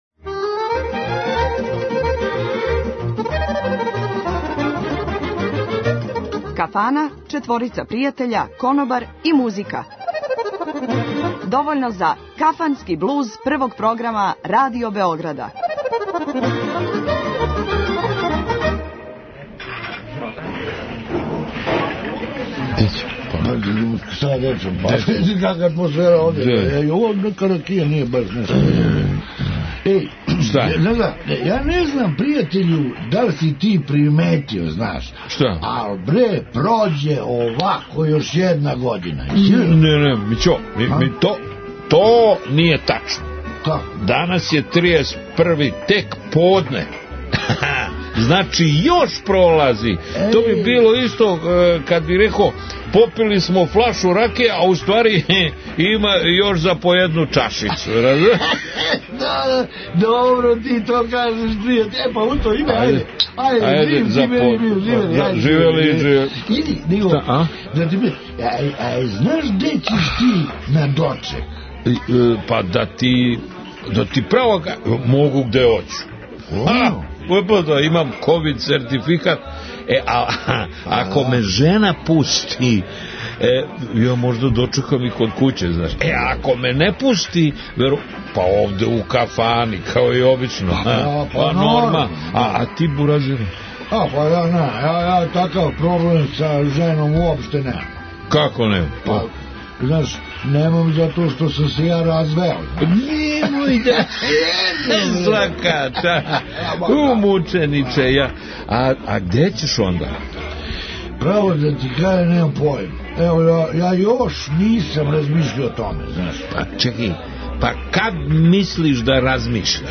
Воле и да запевају!